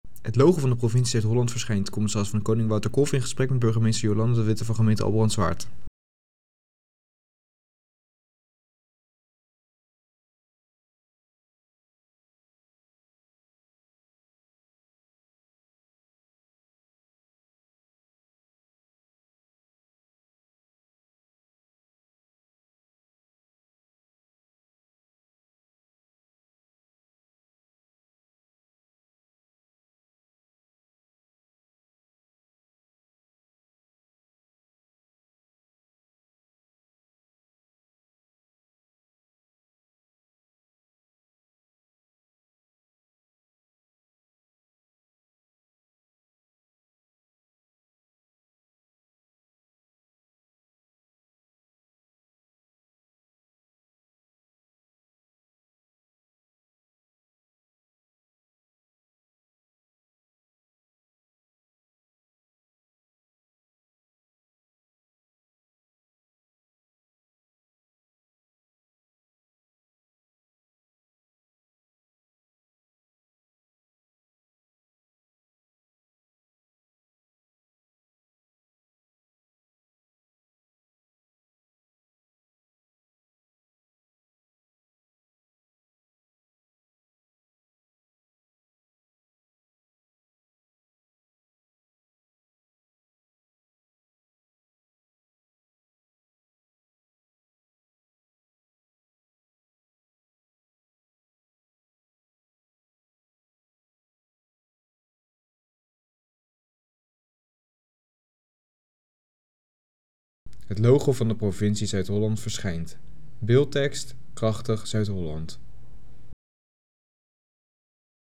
Commissaris van de Koning Wouter Kolff in gesprek met de burgemeester van Albrandswaard.